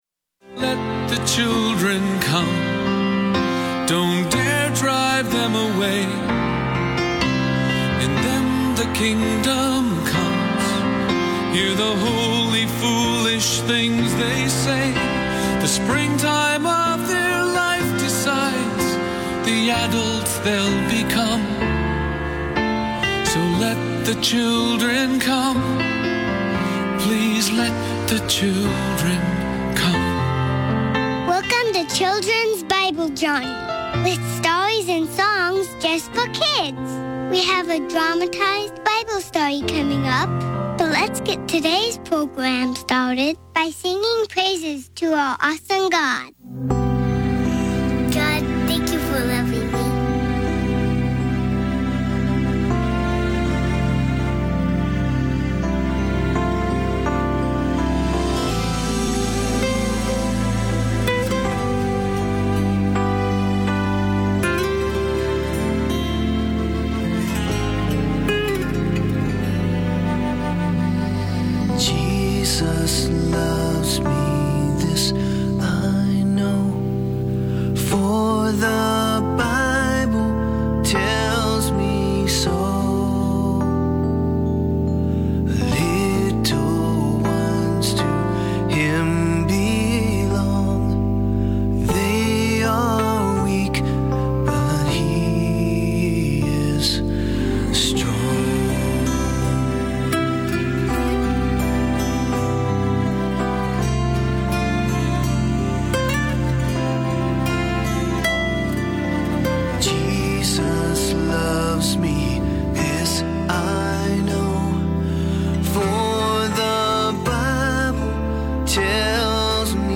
Enjoy a variety of programs for kids in less than 30 minutes.